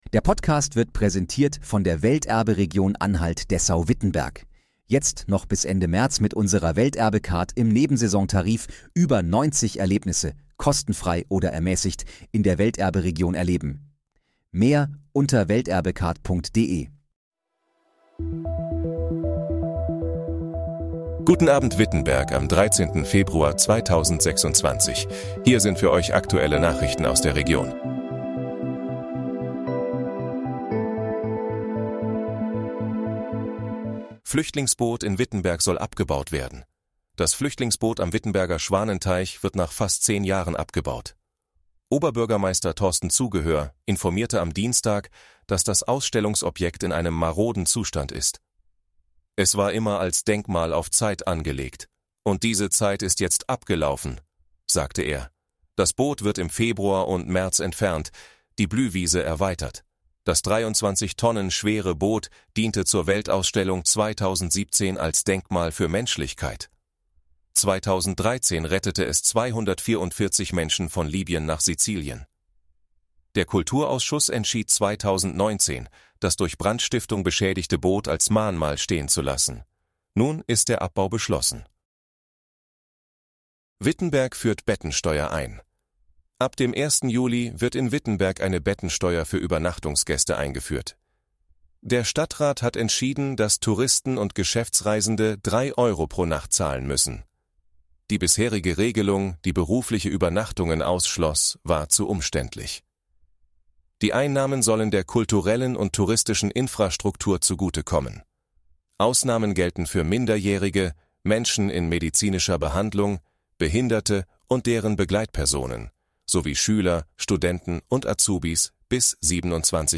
Guten Abend, Wittenberg: Aktuelle Nachrichten vom 13.02.2026, erstellt mit KI-Unterstützung
Nachrichten